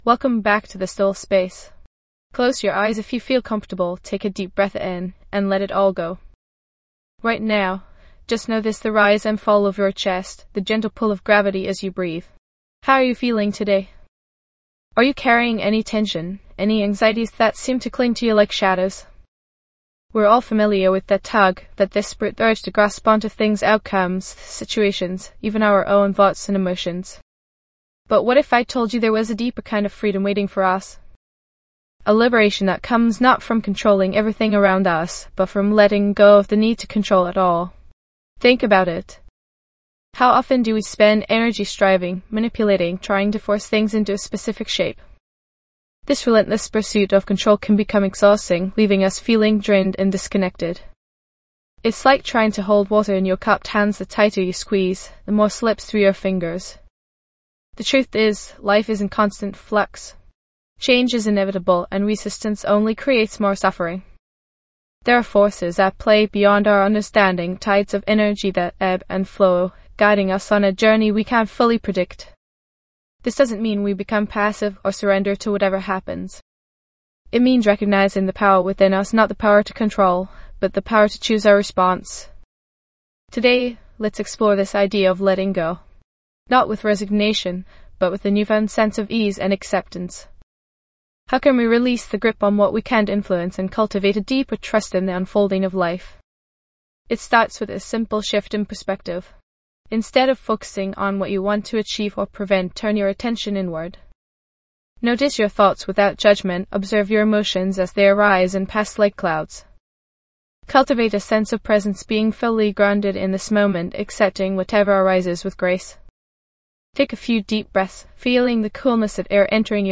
This guided meditation helps you understand the importance of letting go and embracing change, fostering a more relaxed mind and stress-free existence.